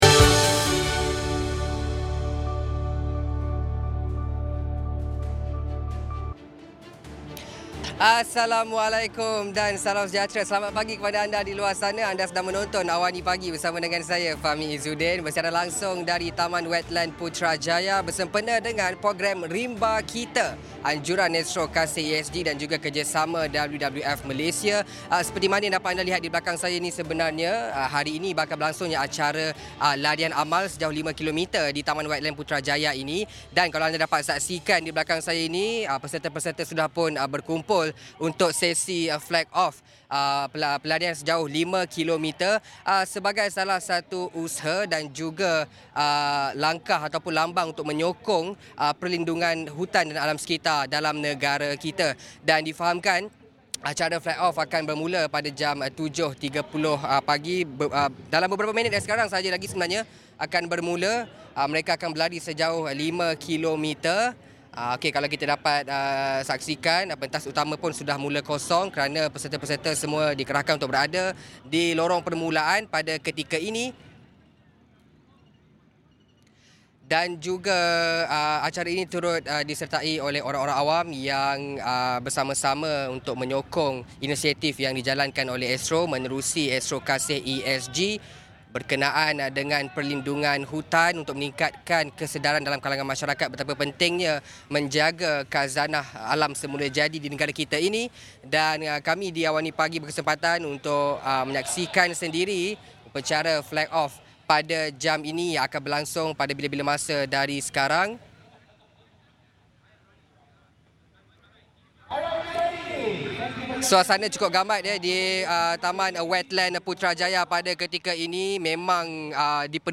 bersiaran langsung dari Taman Wetland, Putrajaya